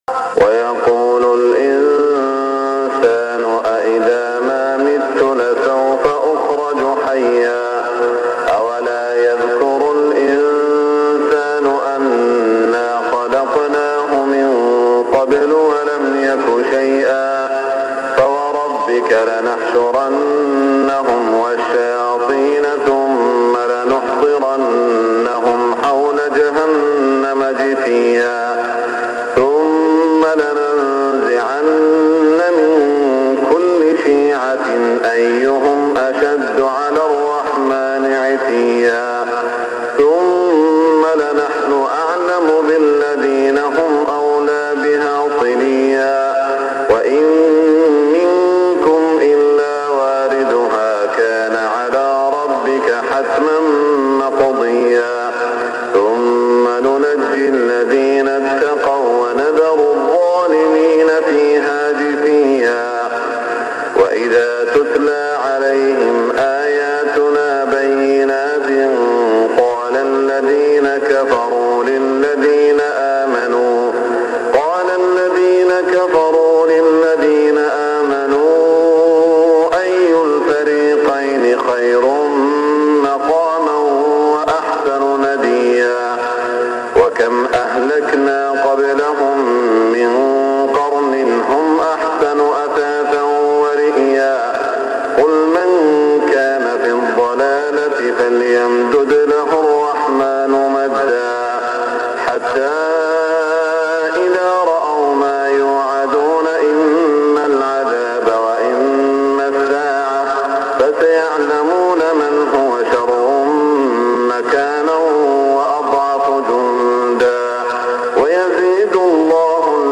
صلاة الفجر 1418هـ من سورة مريم > 1418 🕋 > الفروض - تلاوات الحرمين